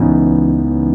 CHORD 2 B.wav